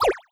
Water5.wav